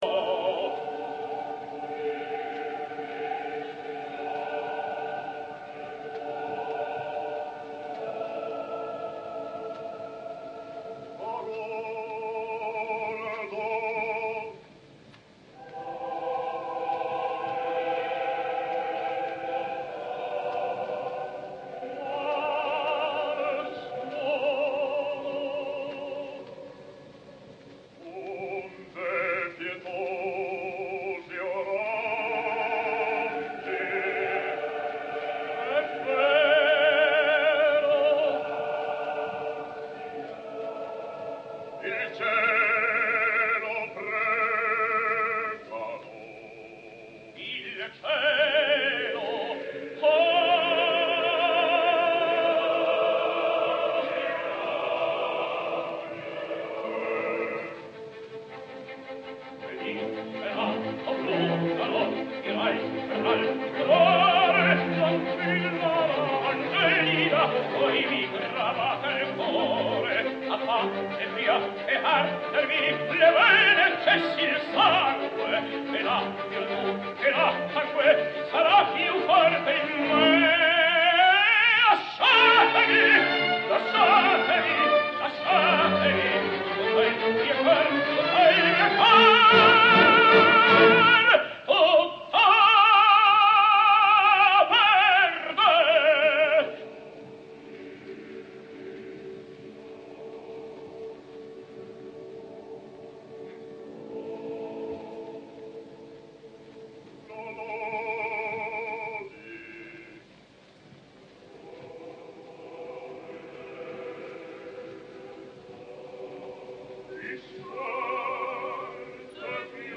opera completa, registrazione in studio.